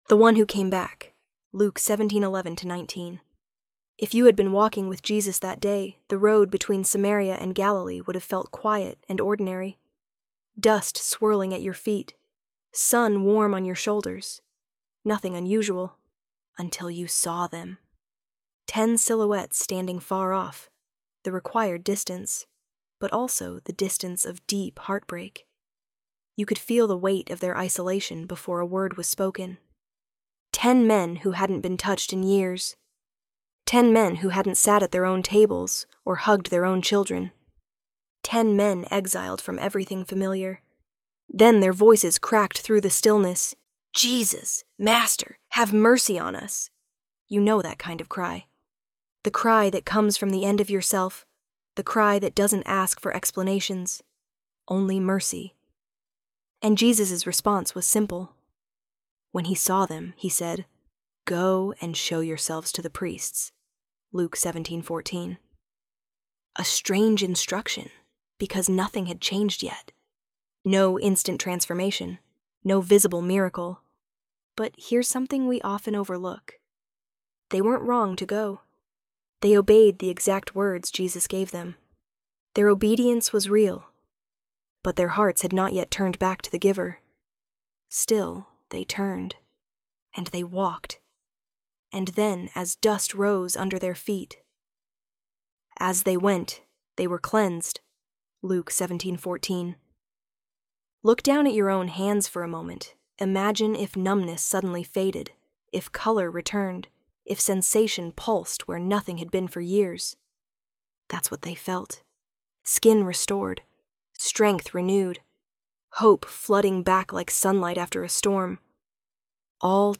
ElevenLabs_THE_ONE_WHO_CAME_BACK.mp3